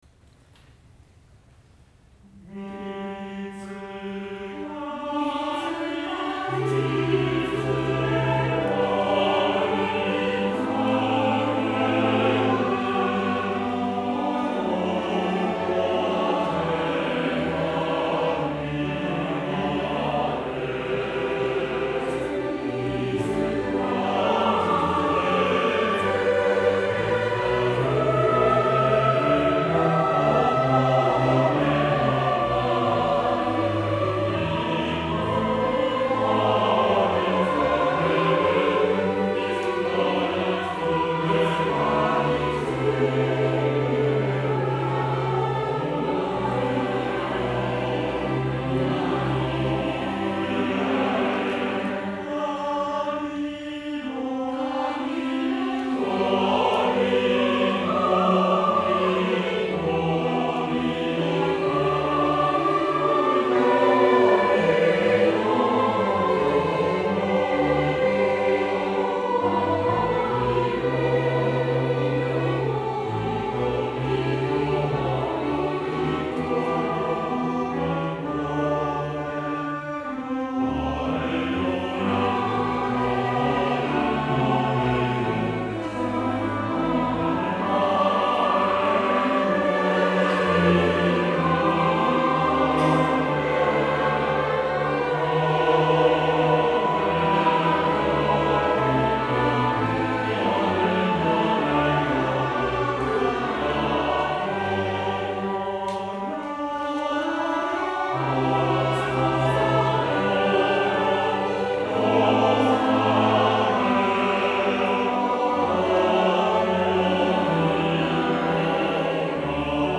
Midi Instrumental ensemble (Fl-Fl-Fl-Fg-Str)